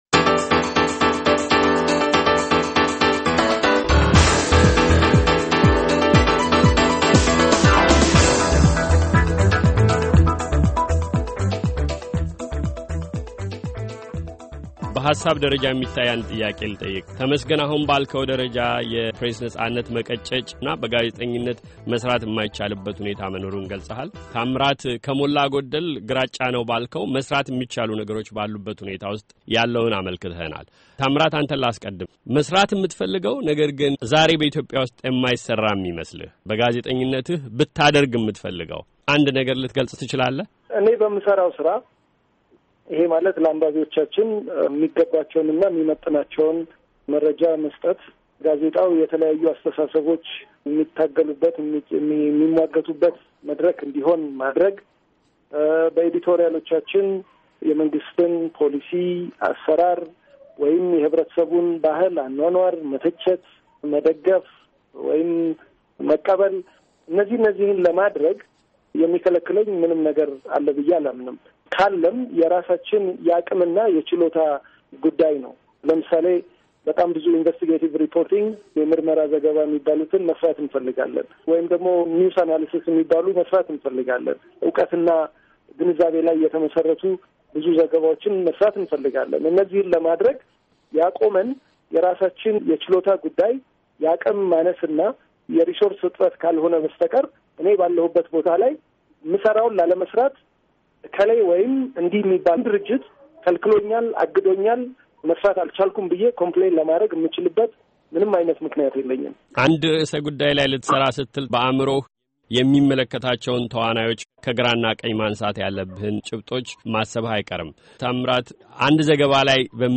ክርክር፥ የኢትዮጵያ የመገናኛ ብዙኃንና የንግግር ነጻነት ይዞታ፤